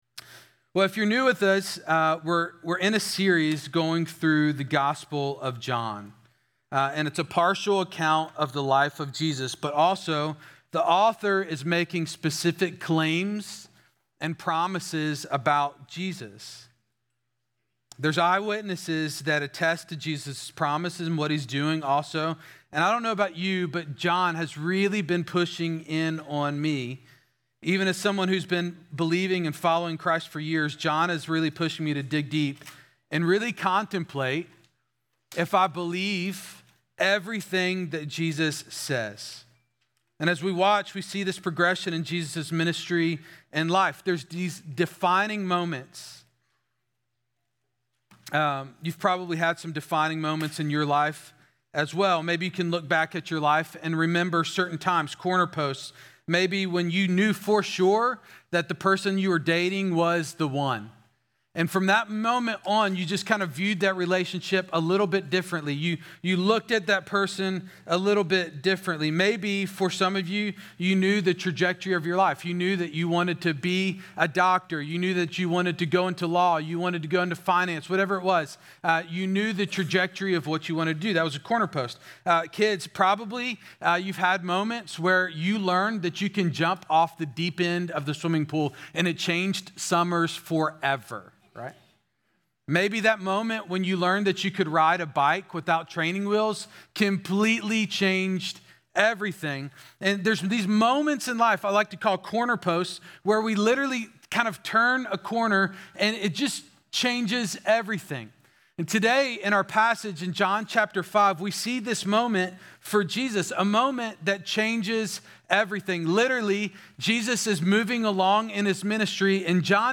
Exchange Church Sermons